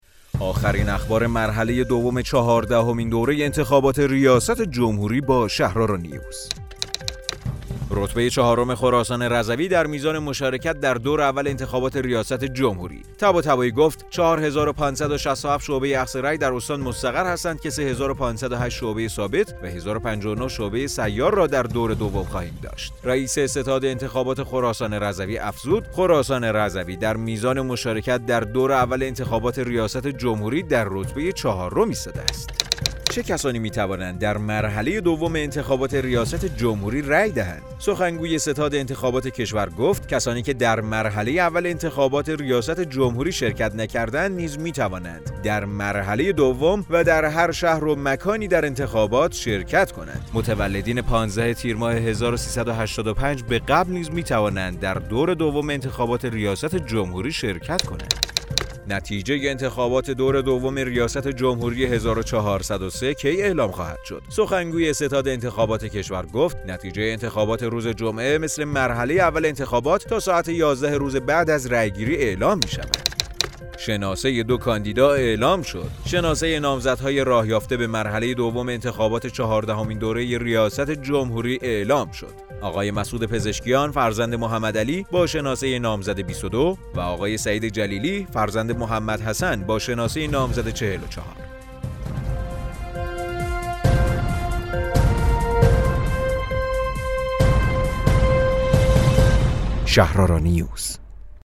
رادیو شهرآرا، پادکست خبری انتخابات ریاست جمهوری ۱۴۰۳ است.